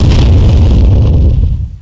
bombexplosionclose.mp3